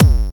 kick1_4g.ogg